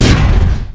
heavyShoot.ogg